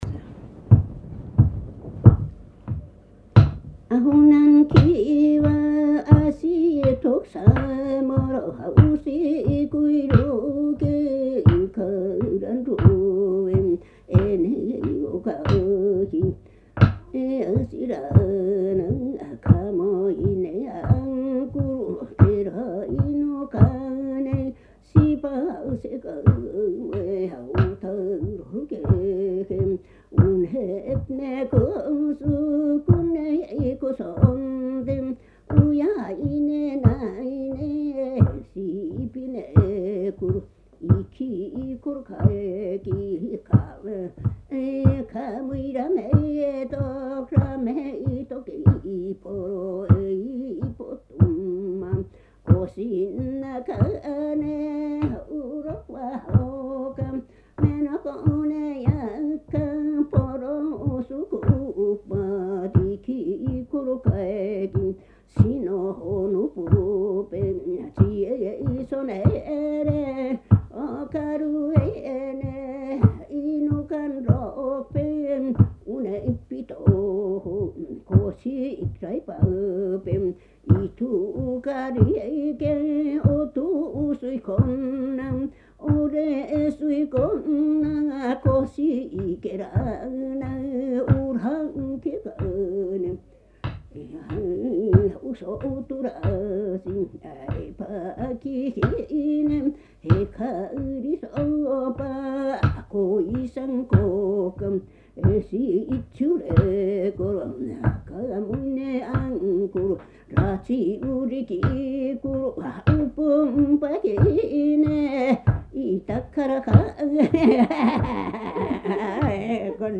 [9-4 英雄叙事詩 heroic epics] アイヌ語音声 11:16